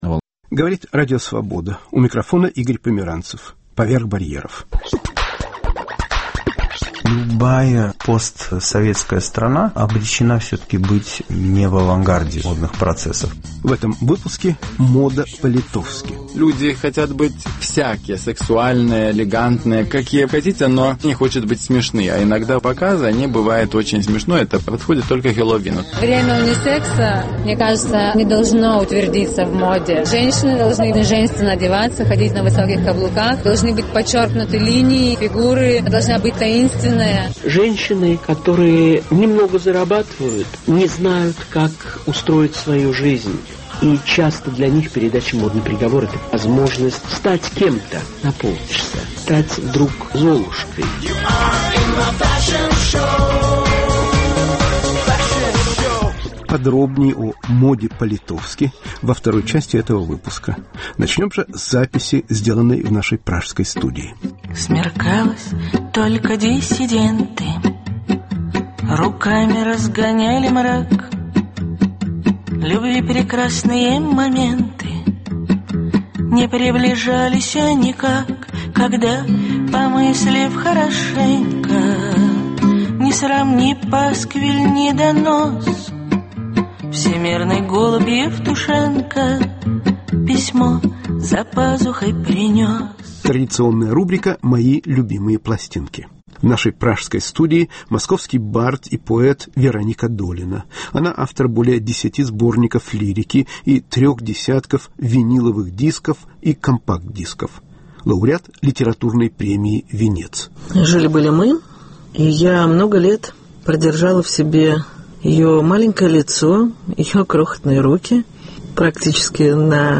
Поёт и рассказывает Вероника Долина